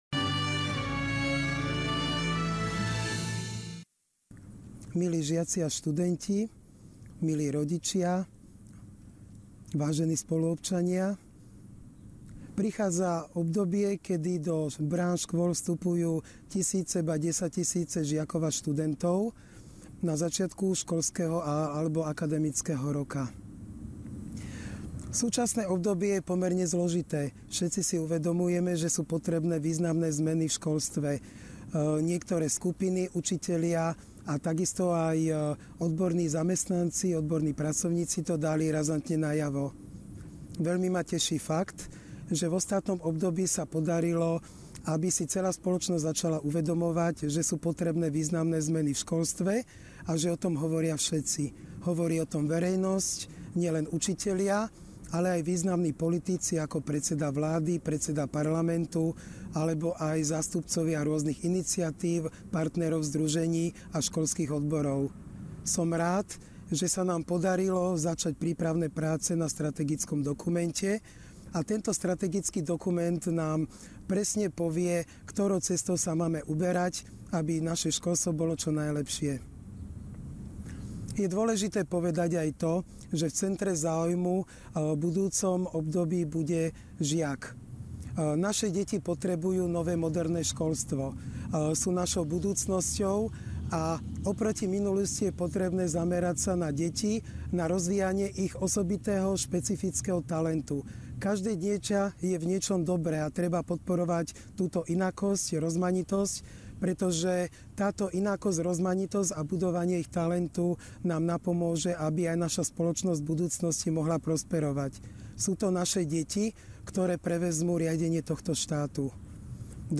Slávnostné otvorenie školského roka 2016/17
príhovor prof. Ing. Petra Plavčana CSc. - ministra školstva, vedy, výskumu a športu SR.
minister_plavcan.mp3